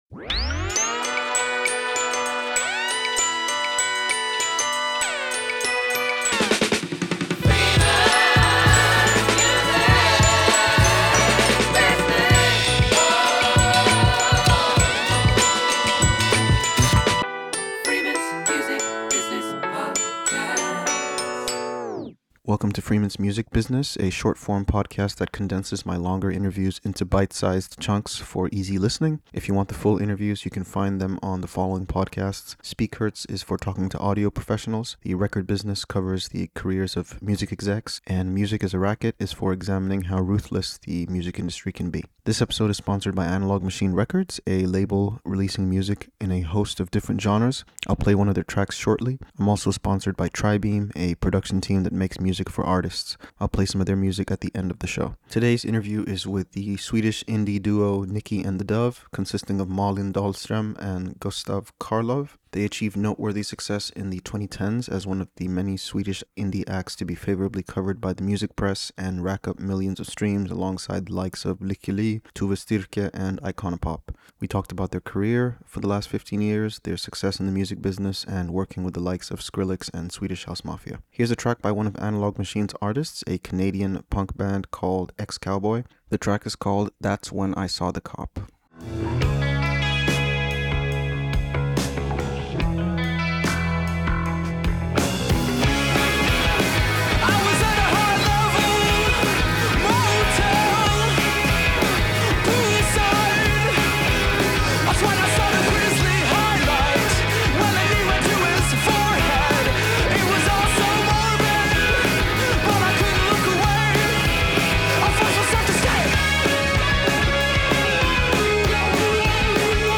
I was able to speak with them about their initial success, being signed to one of Sweden’s biggest indie labels and what the last 15 years has been like for them.